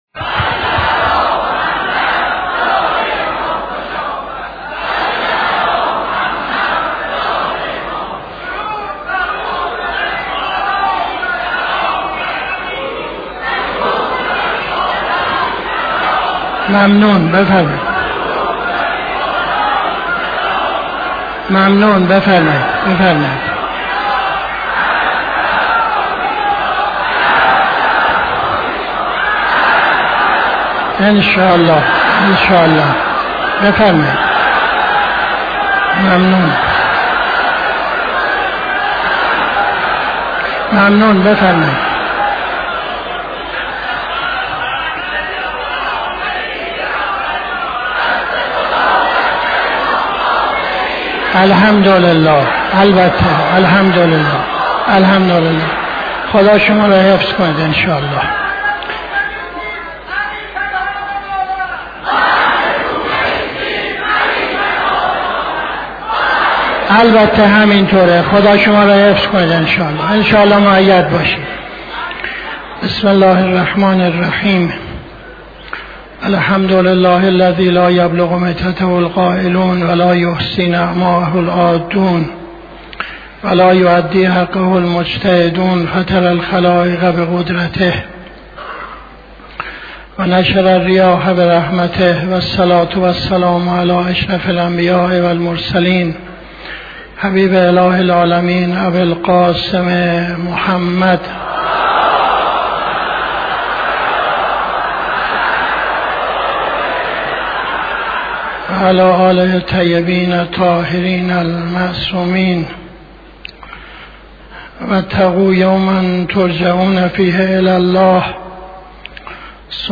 خطبه اول نماز جمعه 01-11-78